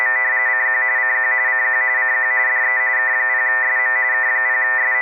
Let's say the ground popped off your audio cable somewhere introducing a horrendous buzz across your passband at 120Hz intervals. Without the AFSK filter, all of these 120Hz harmonics will show up as charming carriers around your signal, which in turn will earn you much endearment from your neighbors.
It is obvious that something is horribly wrong on the K3 audio monitor as well.
Figure 9: 120Hz sawtooth wave generating harmonics across the passband about 15dB below the signal. The AFSK TX filter is not engaged. Reference is the same as Figure 1.
buzz_no_filter.wav